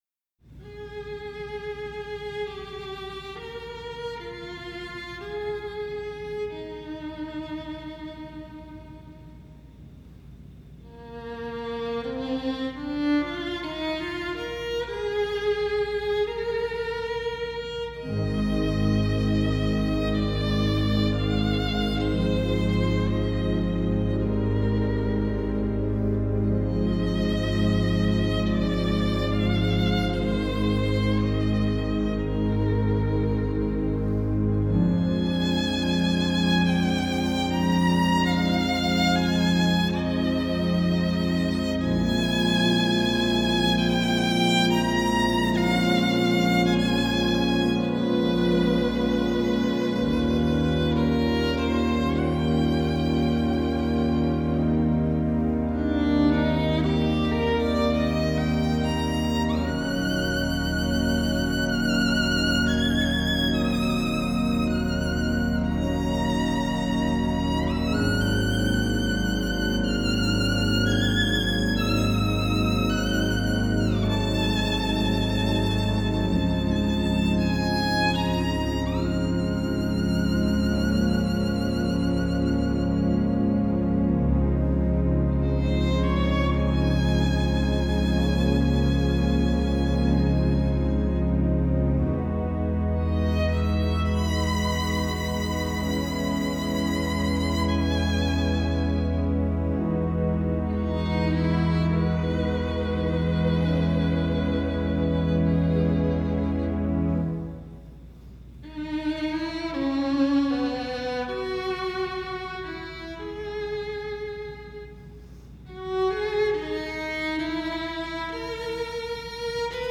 Voicing: Violin